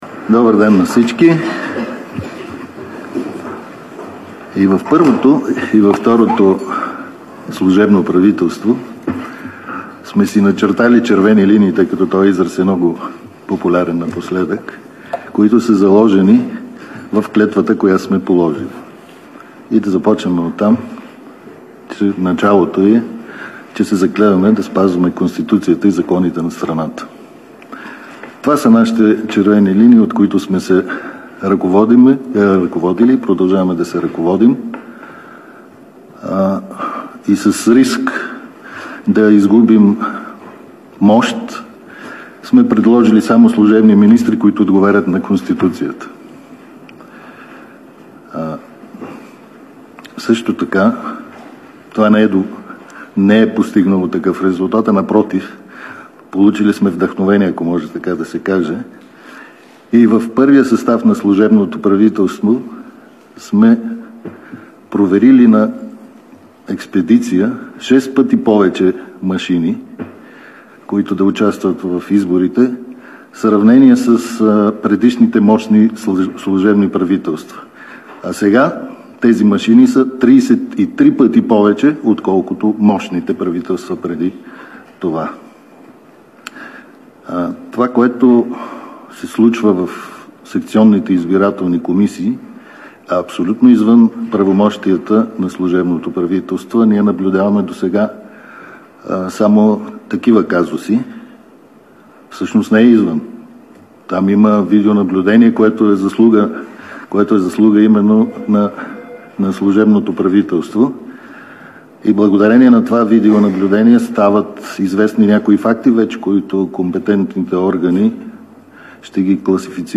11.10 - Реч на Тръмп пред негови симпатизанти.. - директно от мястото на събитието (Вашингтон)